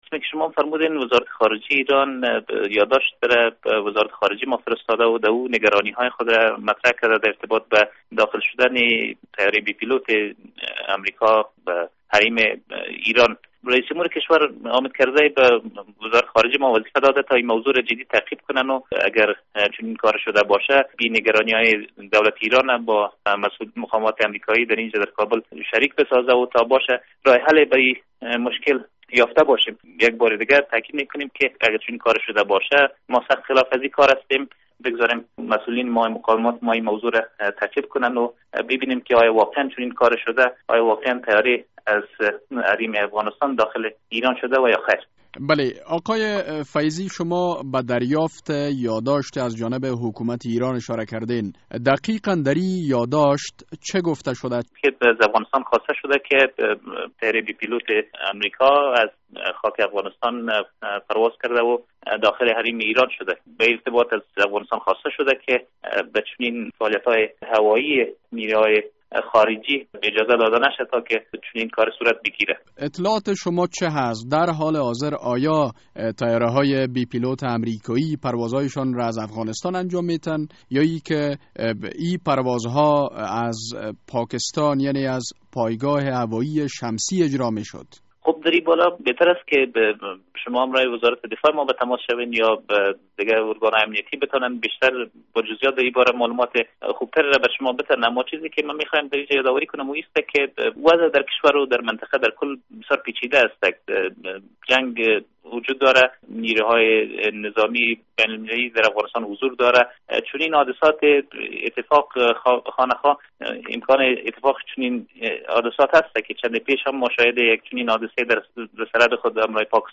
مصاحبه: ایران می گوید، امریکا از خاک افغانستان بر ایران تجاوز کرده است